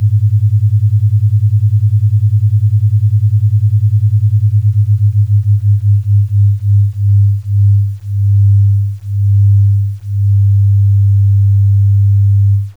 Beats.wav